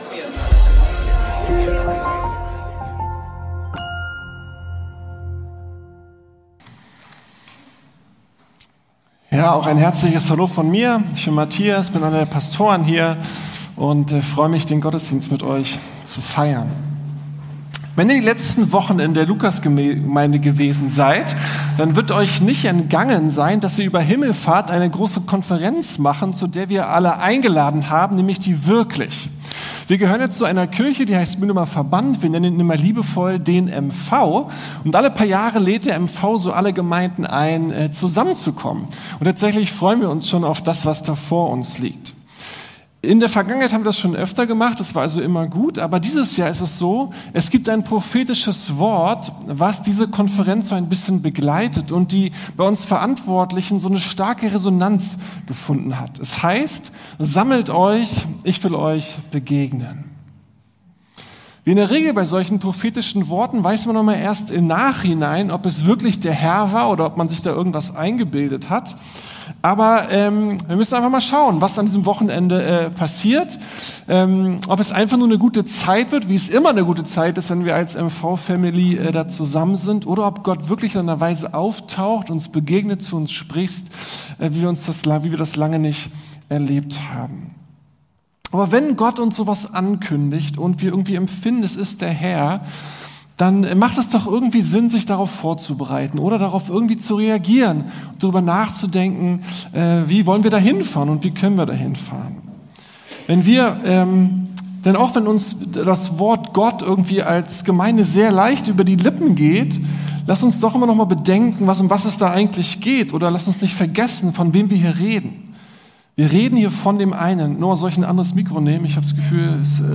Gemeinsam Anbeten - auf dem Weg zur WIRKLICH ~ Predigten der LUKAS GEMEINDE Podcast